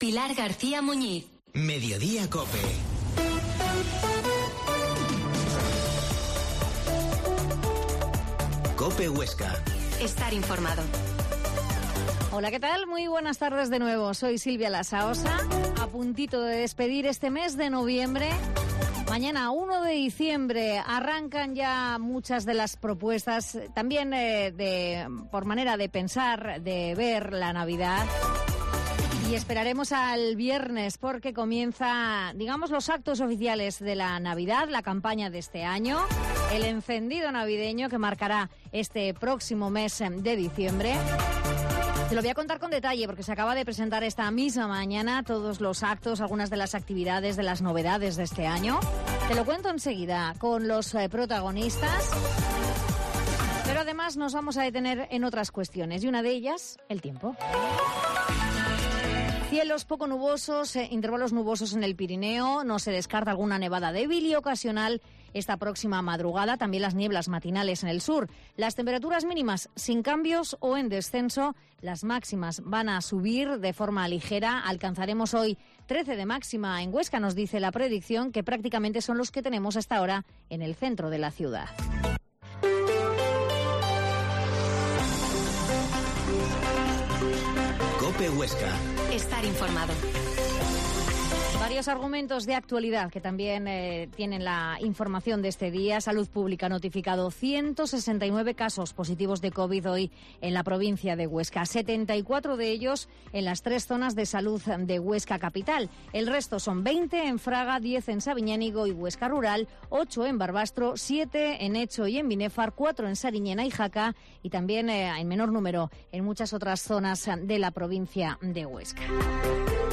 Mediodia en COPE Huesca 13.20h Reportaje de la presentación de la campaña de Navidad